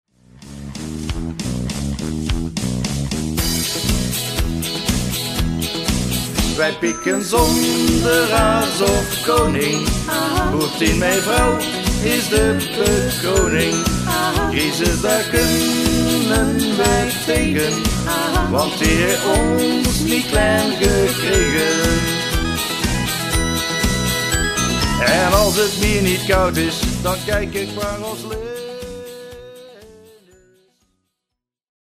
Hee, toch een studioklus tussendoor:
Klinken die koortjes niet bekend in de oren?